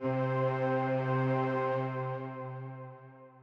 Organ - Orchestra.wav